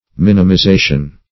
Minimization \Min`i*mi*za"tion\, n.